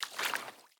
swim2.ogg